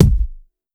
DrKick69.wav